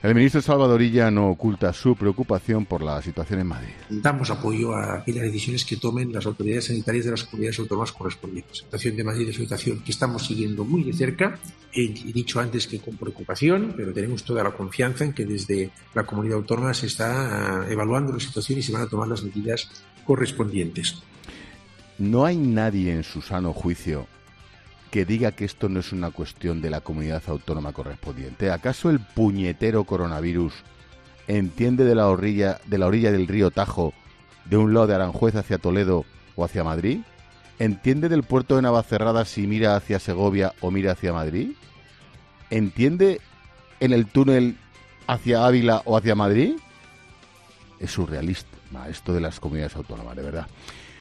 Ángel Expósito ha aprovechado el monólogo inicial de 'La Linterna' de este miércoles para criticar al ministro de Sanidad, Salvador Illa.